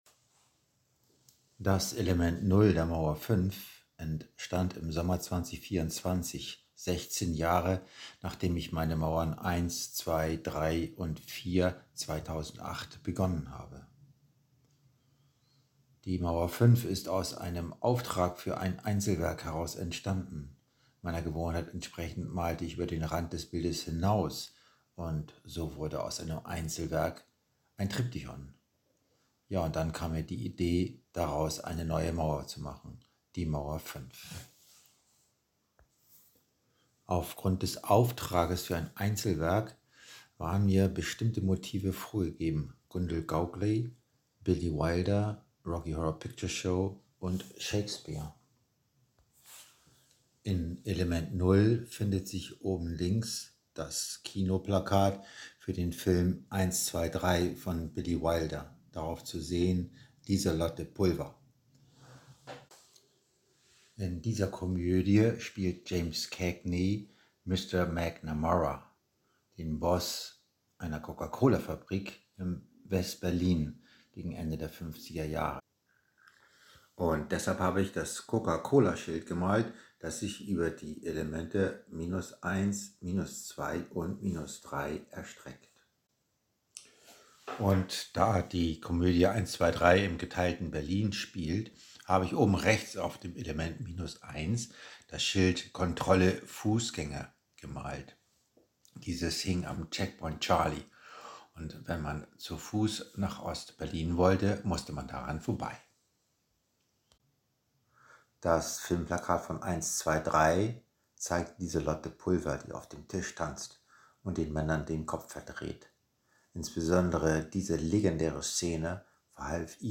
Audio-Beschreibung Mauer V, Element 0 bis minus 3.